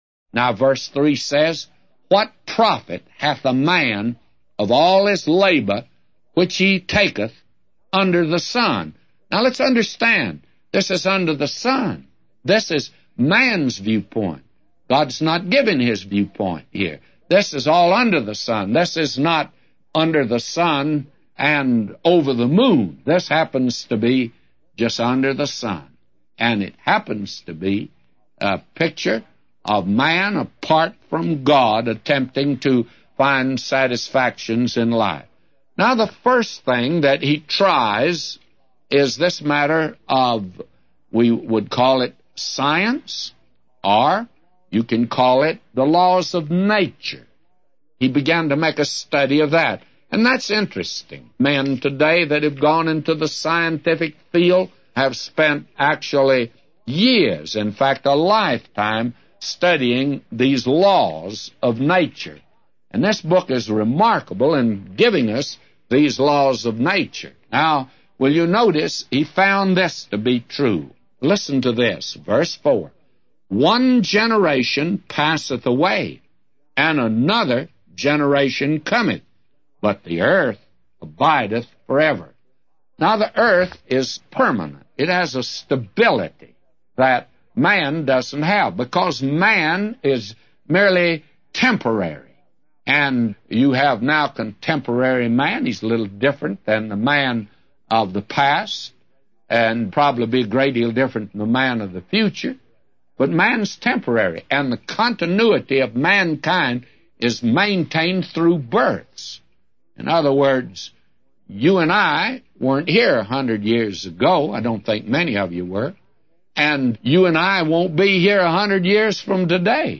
A Commentary By J Vernon MCgee For Ecclesiastes 1:3-999